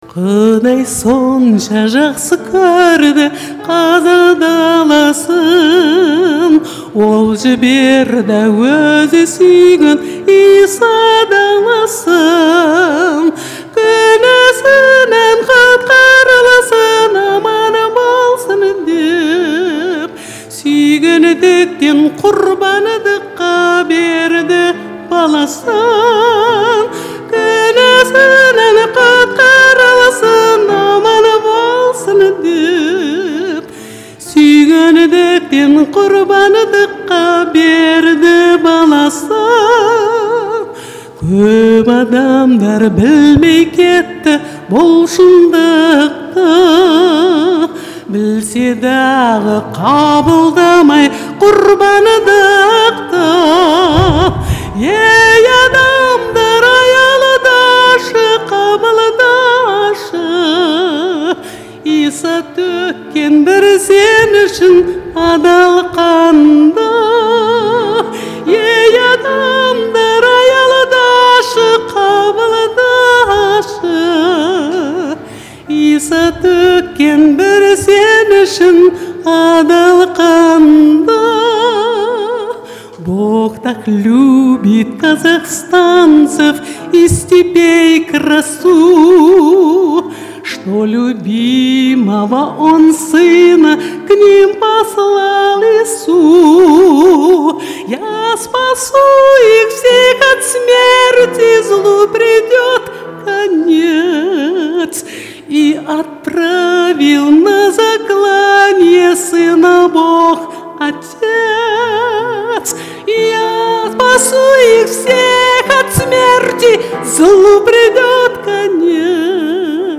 Фестиваль христианской музыки и песни